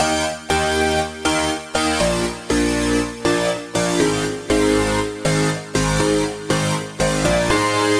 描述：EDM合成器
Tag: 120 bpm Electro Loops Synth Loops 1.35 MB wav Key : G